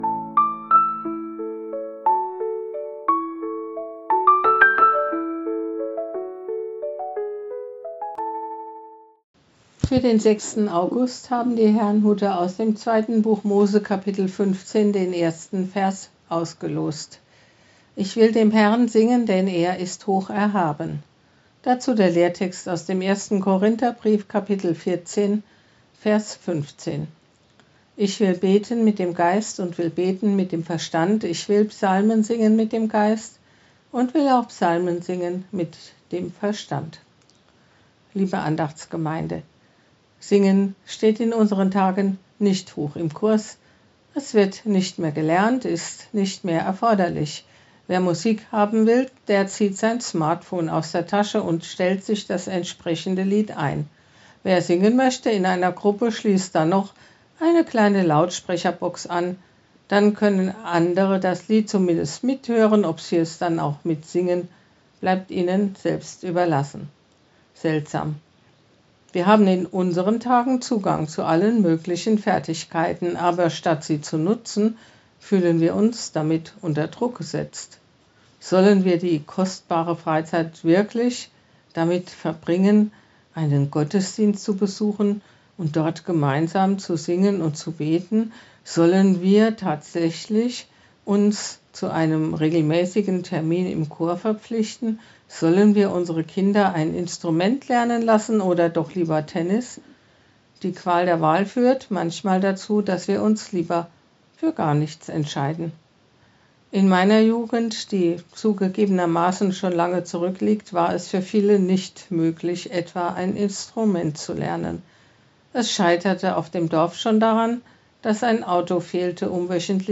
Losungsandacht für Mittwoch, 06.08.2025